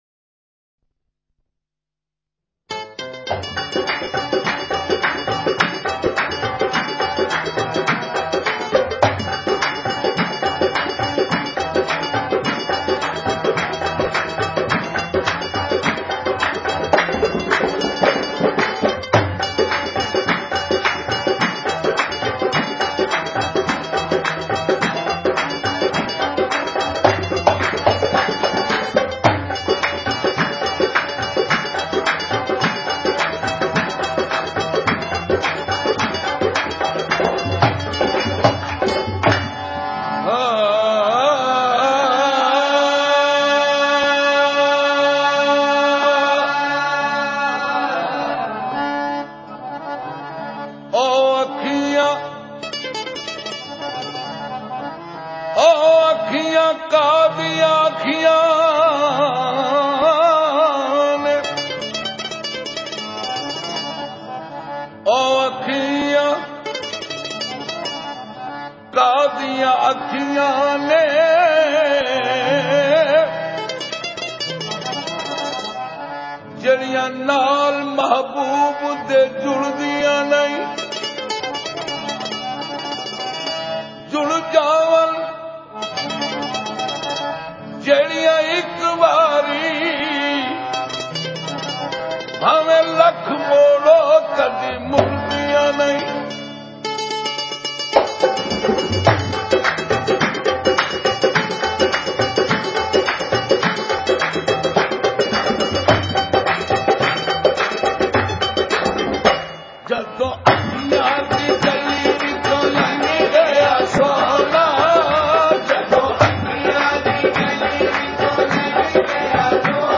Qawwali